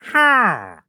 Minecraft Version Minecraft Version latest Latest Release | Latest Snapshot latest / assets / minecraft / sounds / mob / wandering_trader / yes2.ogg Compare With Compare With Latest Release | Latest Snapshot